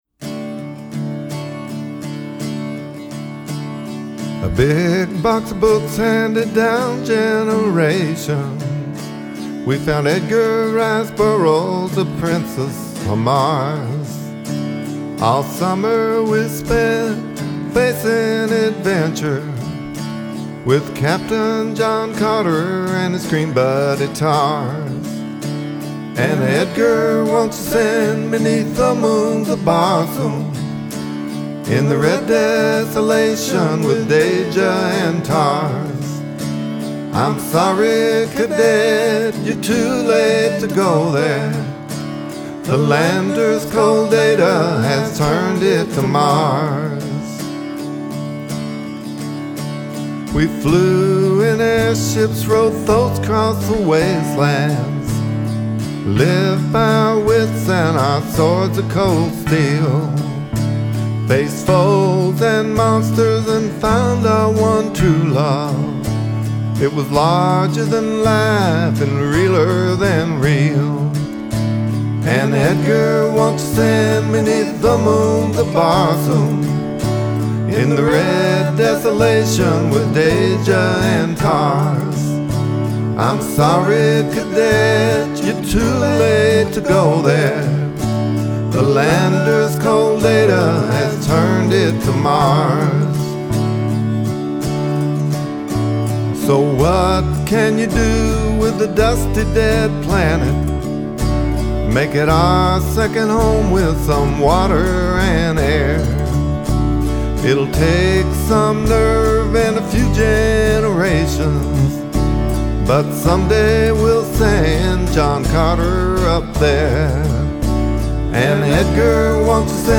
(A Filk Song)